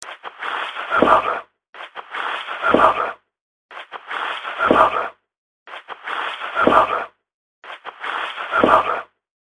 Er et gammelt hus så knirking og knaking kan selvsagt stamme fra naturlige ting...
dette høres ut som "- hawave -" men - hva det skal bety er en annen sak...
Brukte stemmeopptakeren på mobilen og tok opp masse småklipp på litt over minuttet på de ulike stedene som er mest kjent.